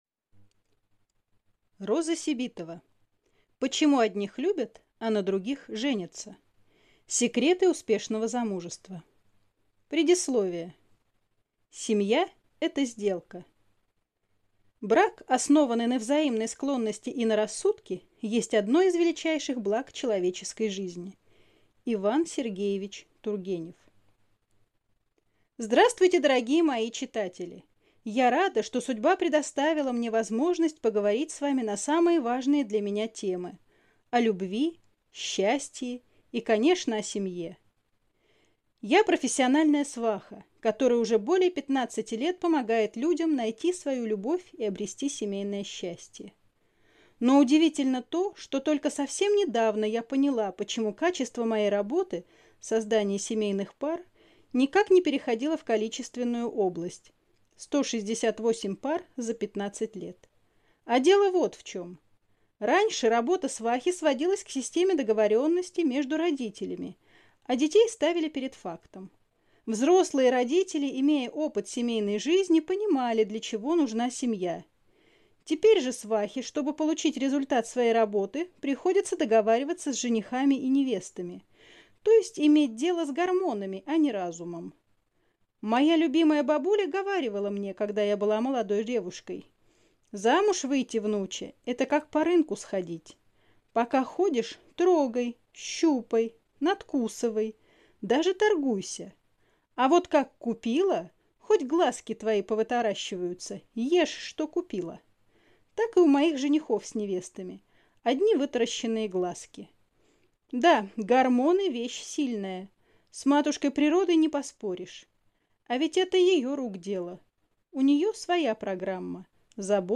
Аудиокнига Почему одних любят, а на других женятся? Секреты успешного замужества | Библиотека аудиокниг